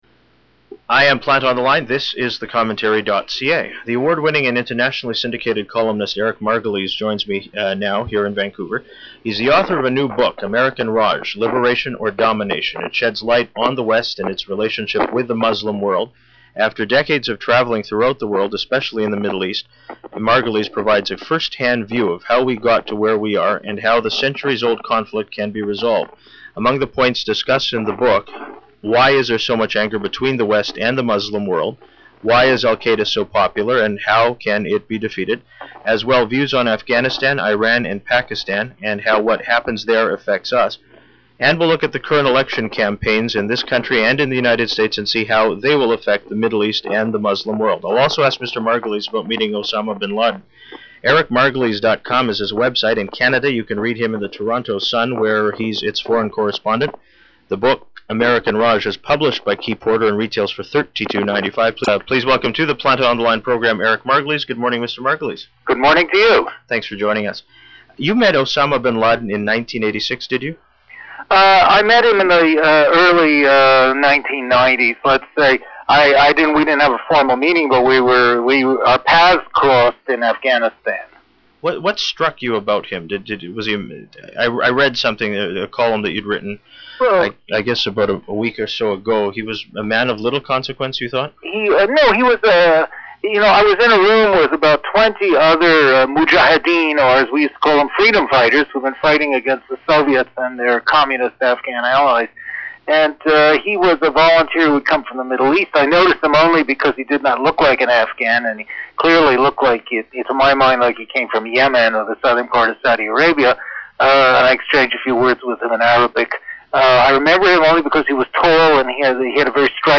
Interview: Eric Margolis